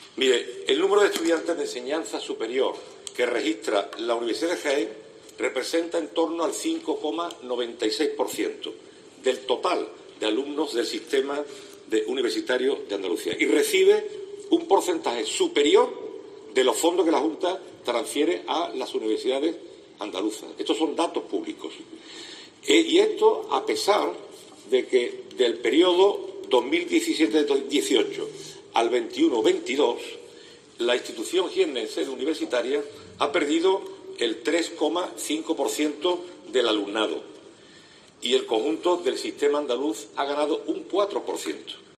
El consejero de Transformación Económica, Rogelio Velasco, durante su intervención en la sesión plenaria.